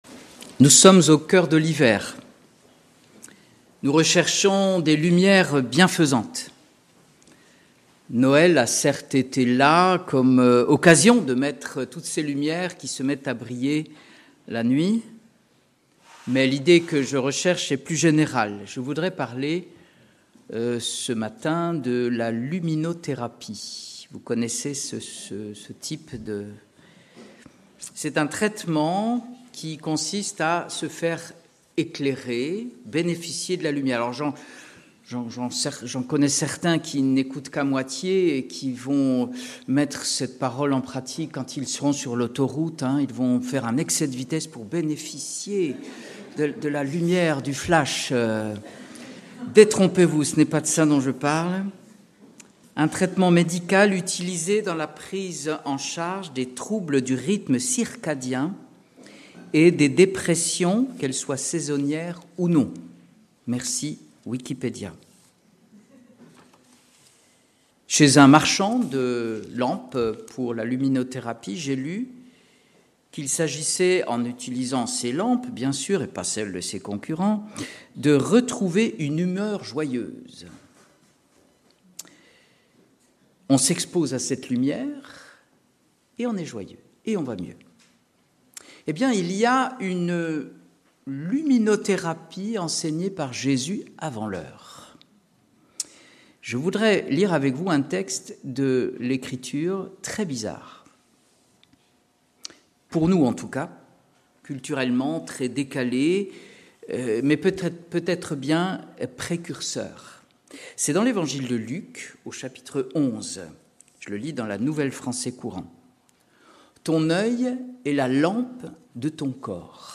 Culte du dimanche 28 décembre 2025 – Église de La Bonne Nouvelle